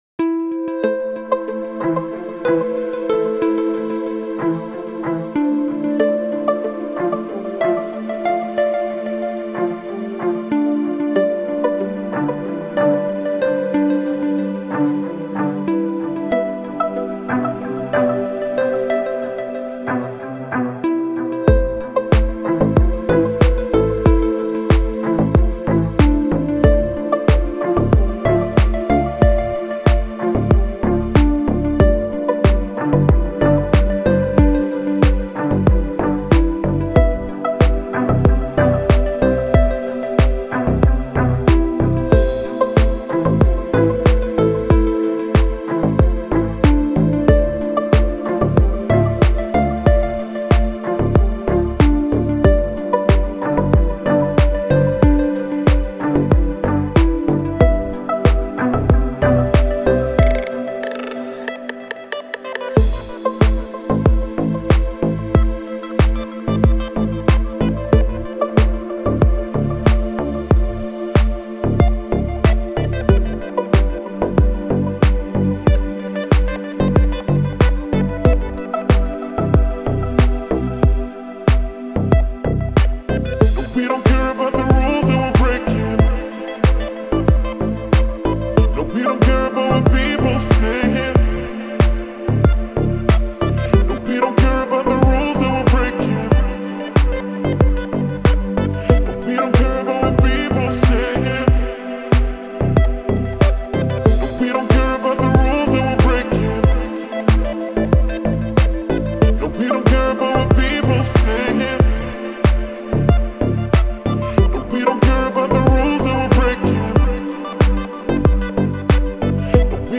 دیپ هاوس , ریتمیک آرام , موسیقی بی کلام